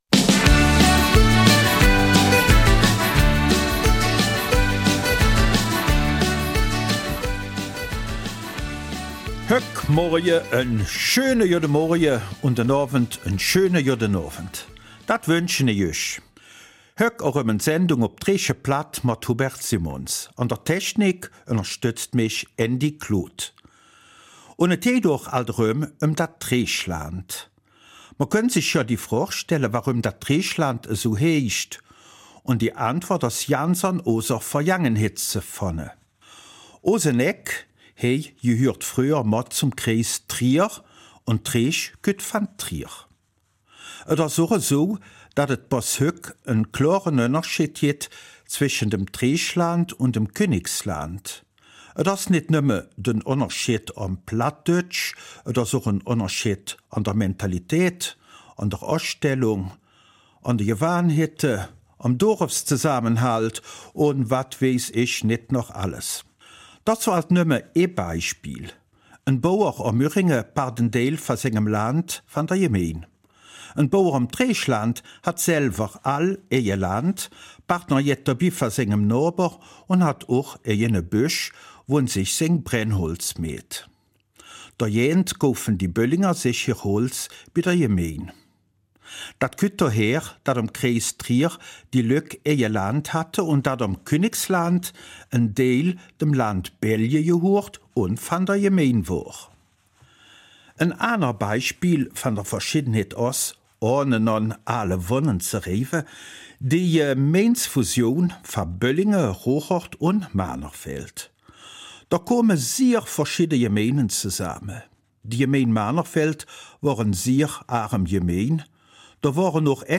Eifeler Mundart - 26. April